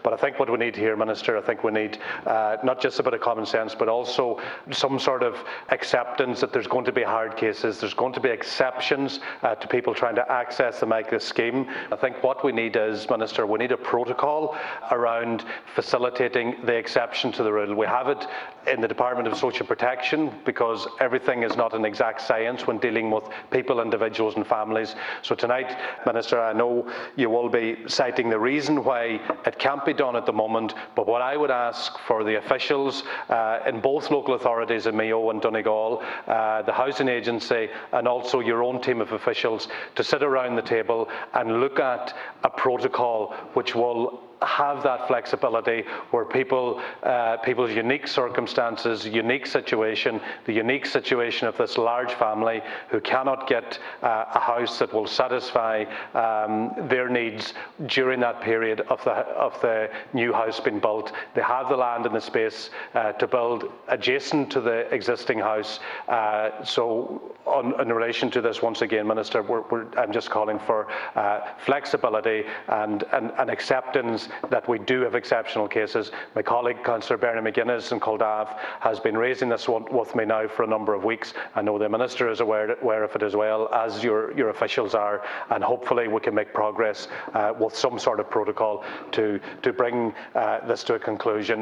Donegal Deputy Joe McHugh spoke in the Dail last evening of a large family including children with specific medical needs whose home is reccomended for demolition due to Mica who he says are not in a position to move out of their home while a new one is being built.